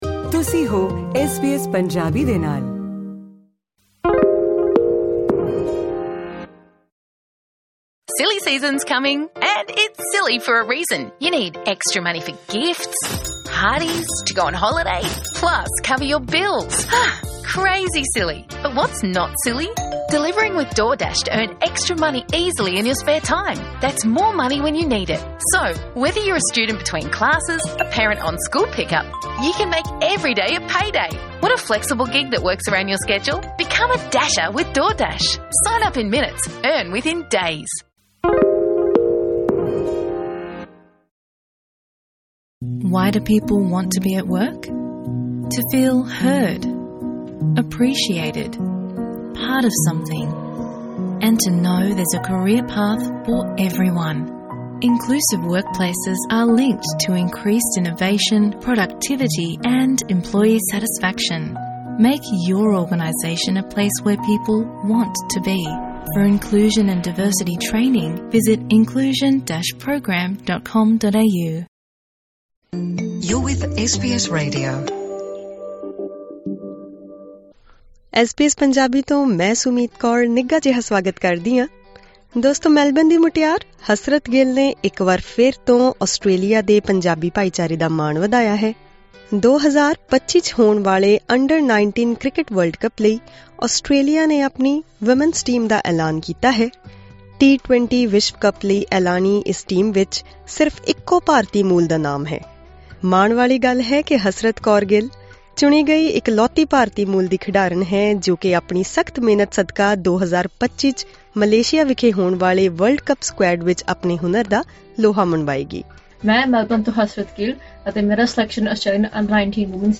Listen to her exclusive interview in Punjabi as she prepares for the big stage.